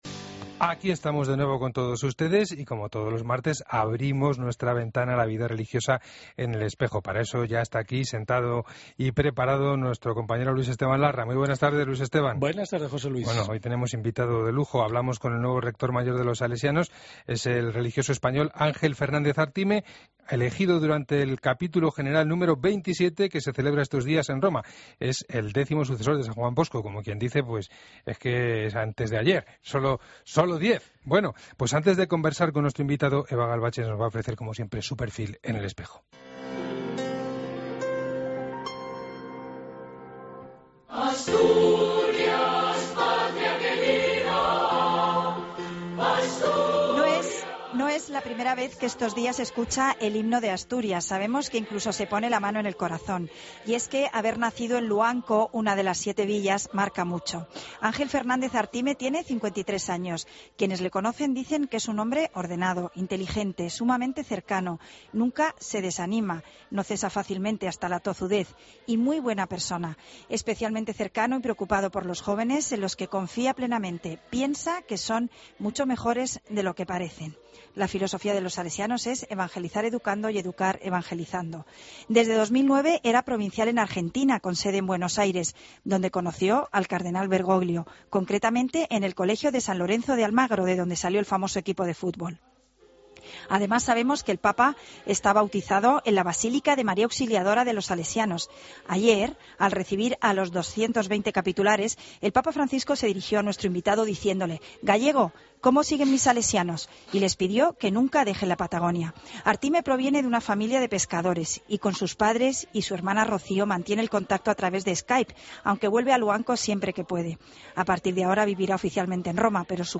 AUDIO: Escucha la entrevista completa al padre Ángel Fernández en 'El Espejo' de COPE